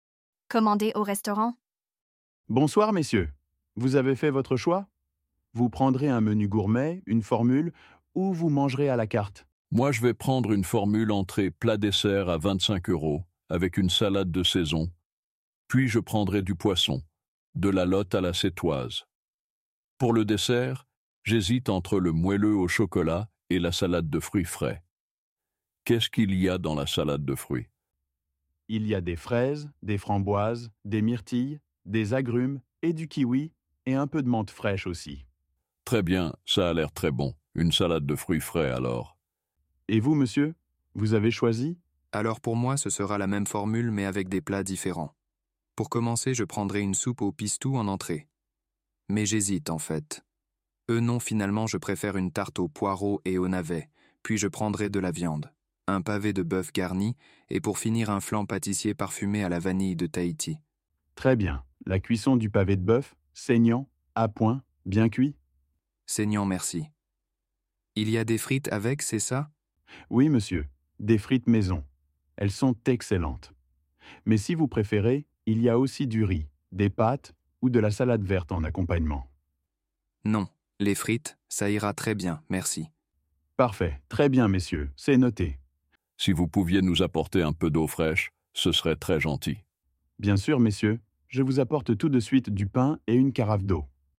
Dialogue FLE - Commander au restaurant | LivreFLE – Dialogues en français